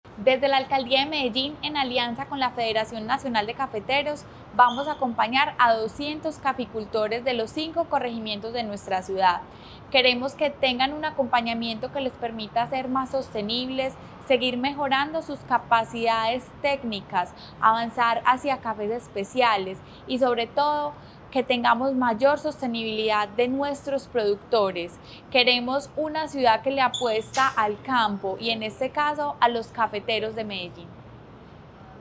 Declaraciones de la secretaria de Desarrollo Económico, María Fernanda Galeano Rojo.